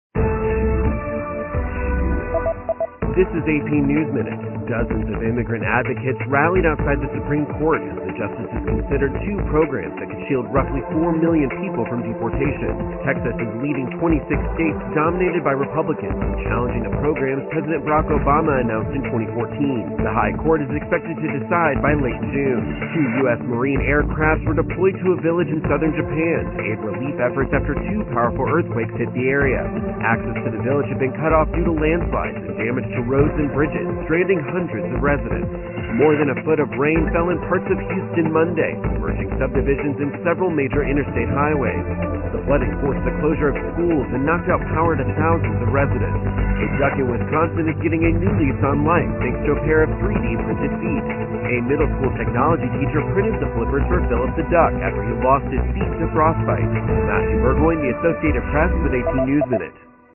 美语听力练习素材:美军出动鱼鹰飞机救援日本地震灾区